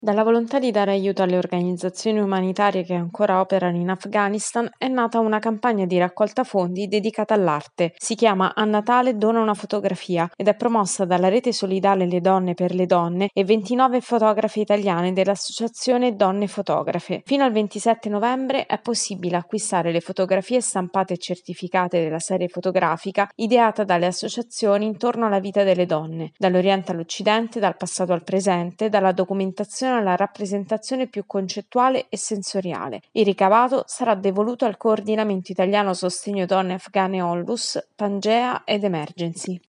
Una campagna di raccolta fondi a sostegno delle associazioni che operano in Afghanistan. Il servizio